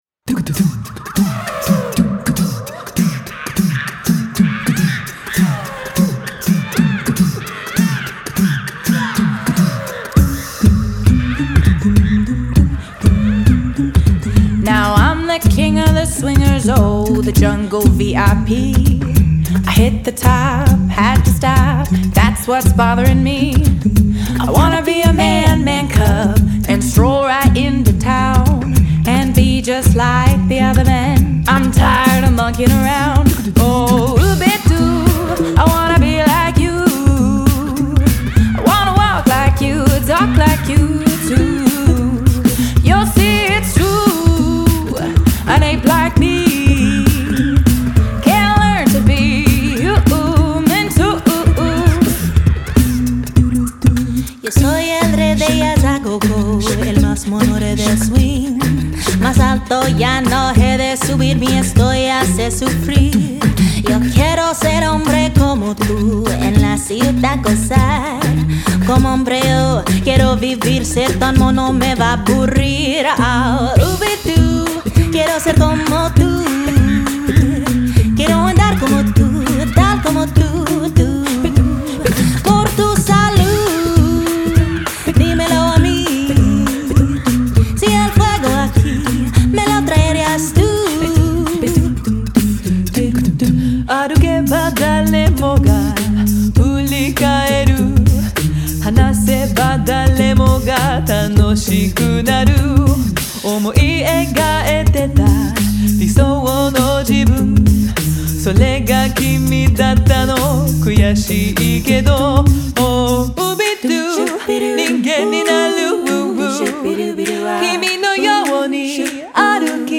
It’s fresh, energized, yet nostalgic.